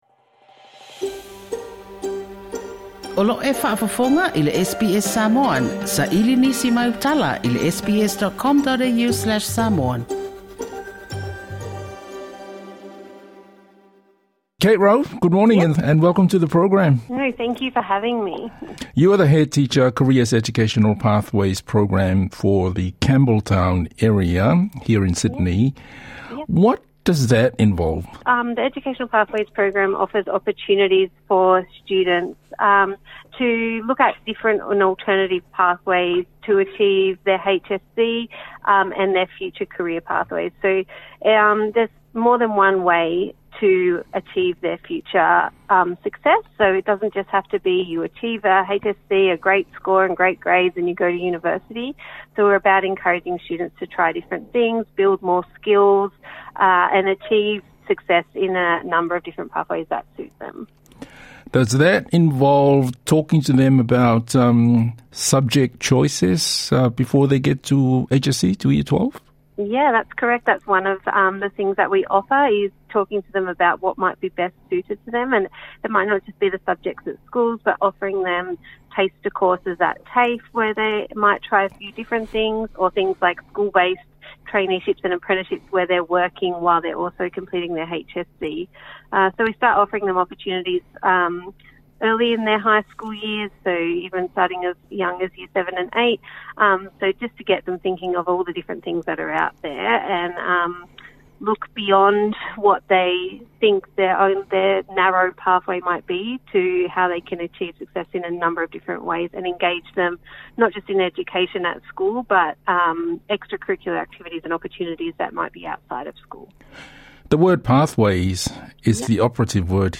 Our Talanoa this week